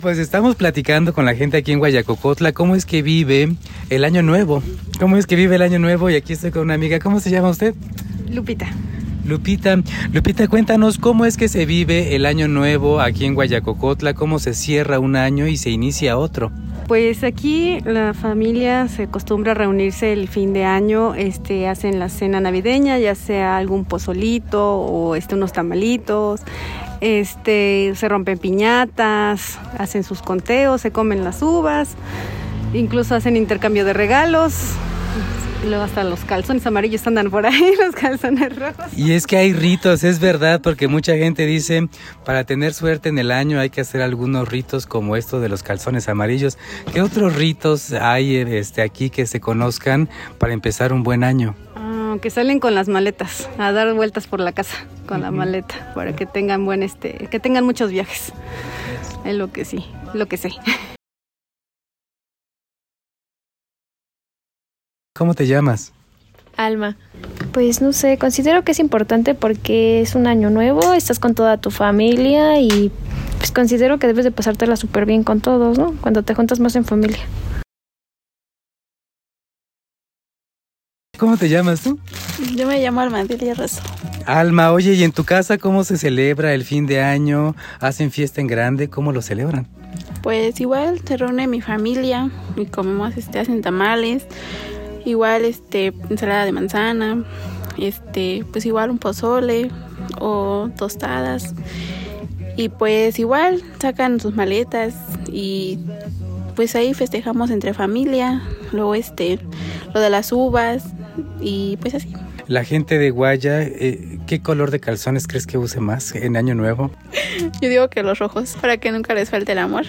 Compartimos los reportes:
Desde Huayacocotla Veracruz